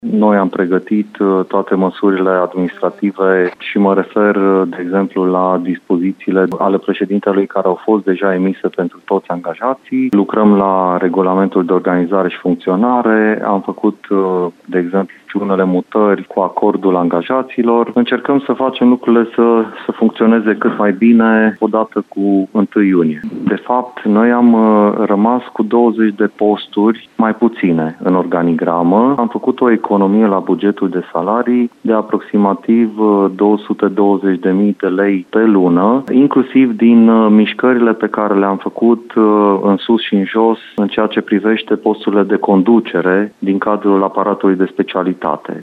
Un număr de 20 de posturi dispar din schema de personal, spune administratorul public al județului, Marian Vasile.